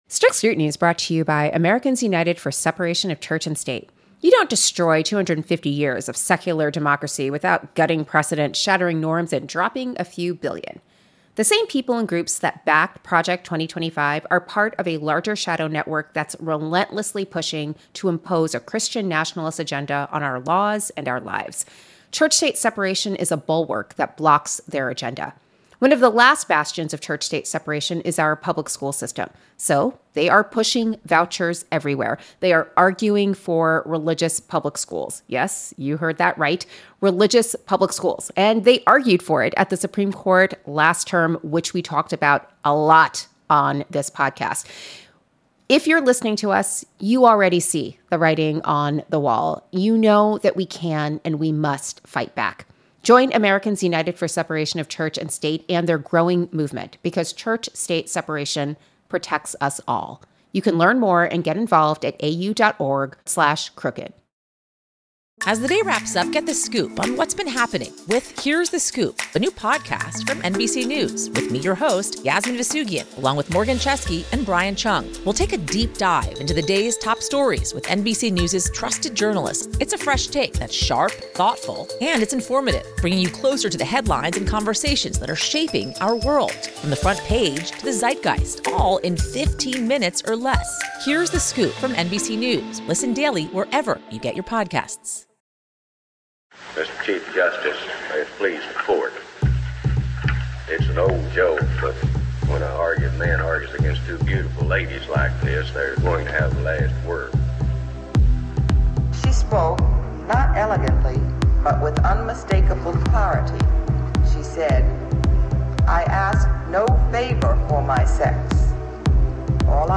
Katie Phang, independent journalist and trial lawyer, joins Leah to run through the week’s legal news–and there’s a lot of it!
Then, all three hosts are joined by Strict Scrutiny’s official roadie, Chris Hayes, to talk about his book, The Sirens' Call: How Attention Became the World's Most Endangered Resource.